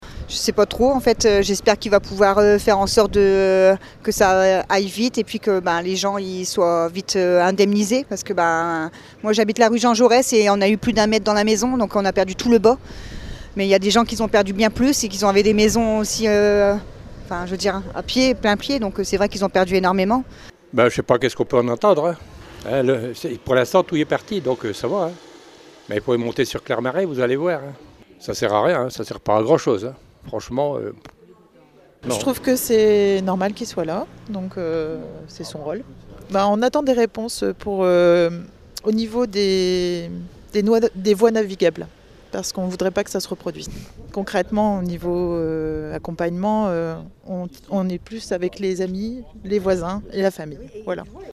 HP ILLUSTRATION Du coté des sinistrés les avis étaient partagés mais plutôt positifs